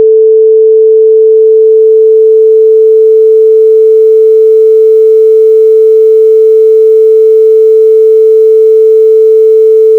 I built a simulation of a pure 440Hz tone being forced through a medium with a \gamma \approx 0.724 threshold.
It’s the Barkhausen Crackle—that granular, irregular snapping in the background. That isn’t just noise; it’s the sound of the magnetic domains physically flipping.